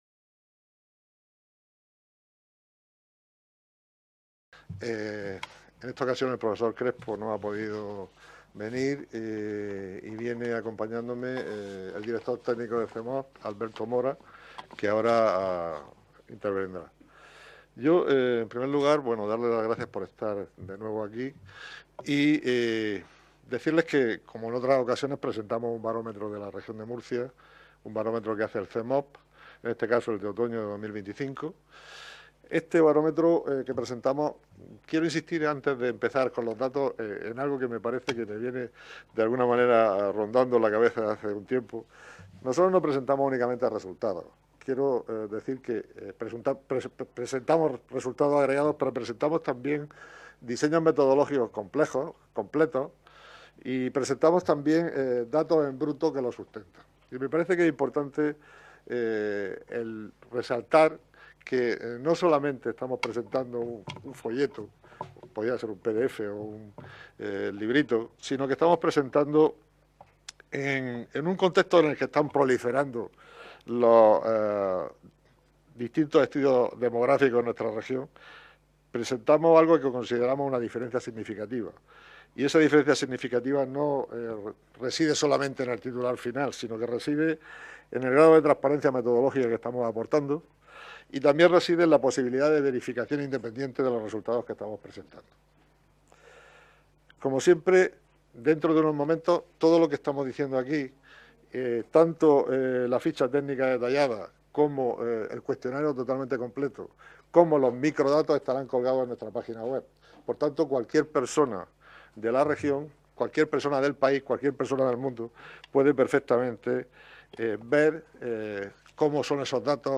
Rueda de prensa tras la presentación del Barómetro de otoño 2025 del CEMOP | Asamblea Regional de Murcia